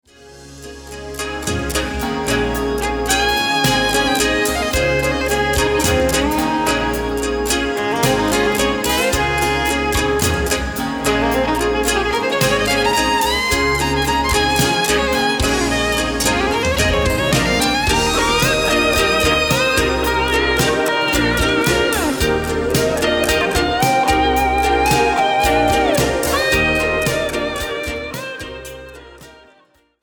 re-mastered, re.engineered sound, bonus songs, bonus videos